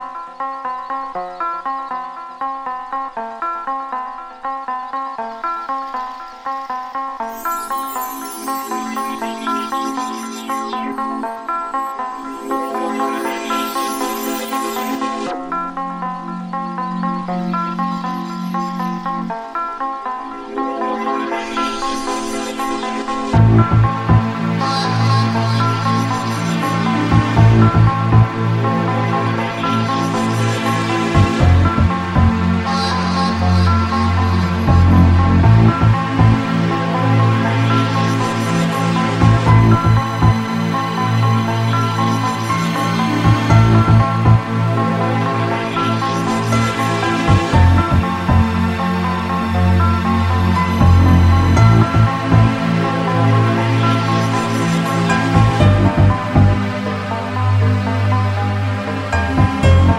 Электронная
Дебютный альбом легенд английского прогрессив-хауса.